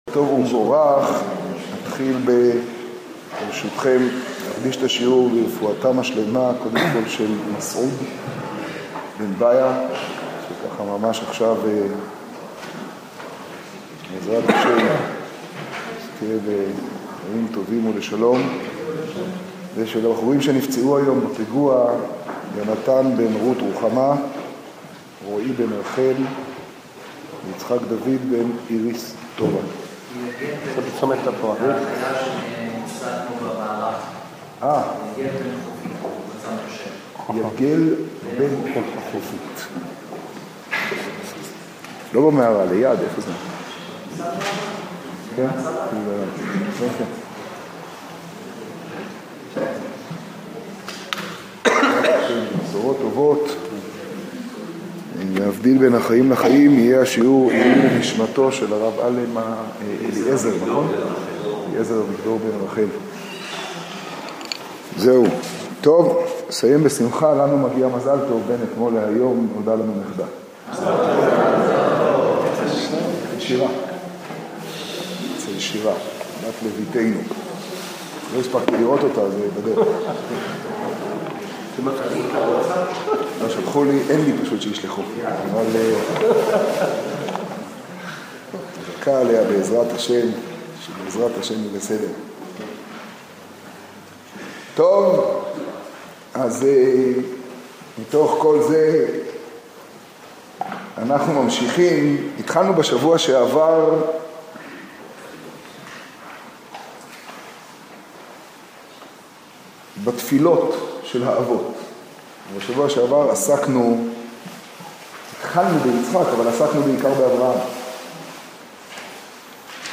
השיעור בירושלים, פרשת תולדות תשעו.